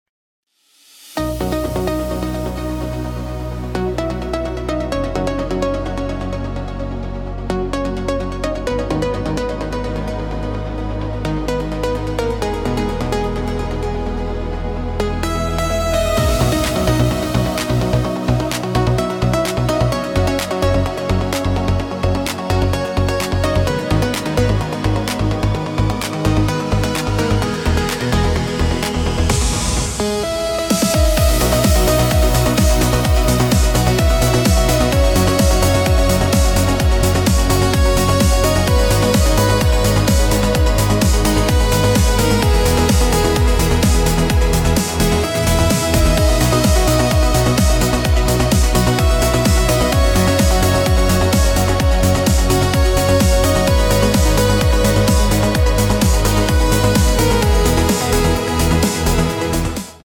Discomusik mit Synthesizer
Mal etwas flotte Musik: Disco im Synthesizer Style.